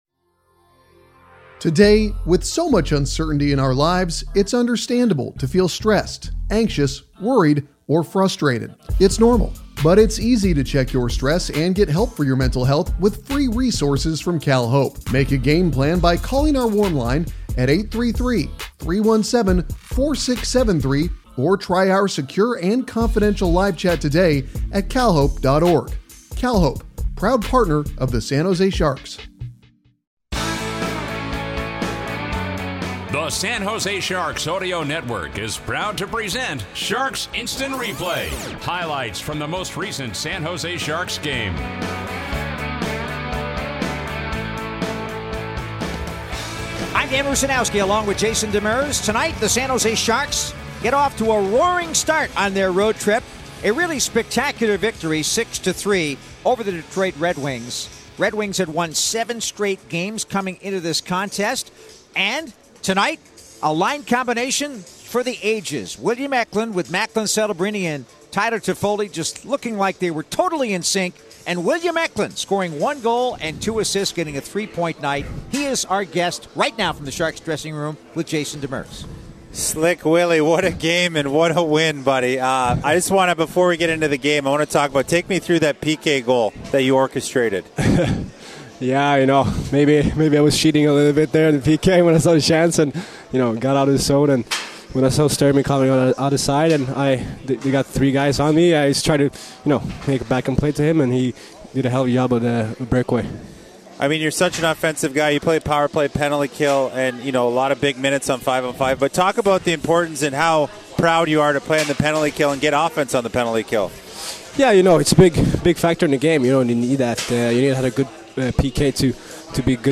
Game 46: SJ 6 at DET 3 FINAL. Included: Postgame interview with William Eklund.